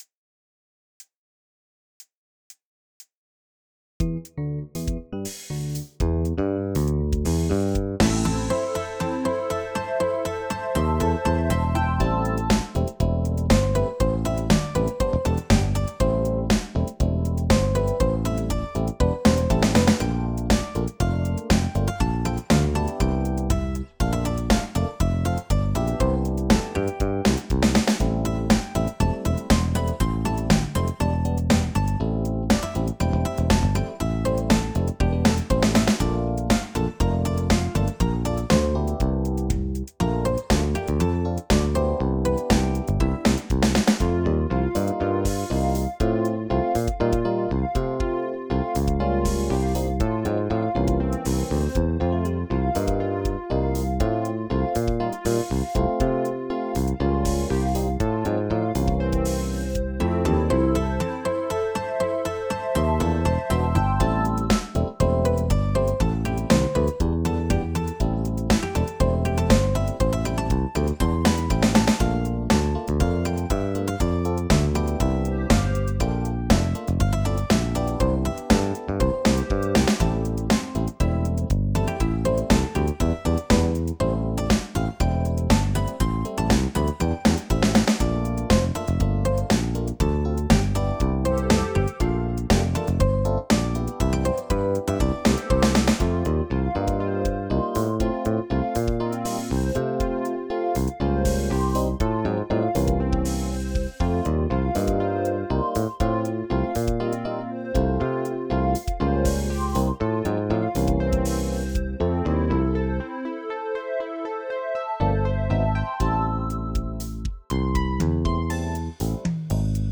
120bpm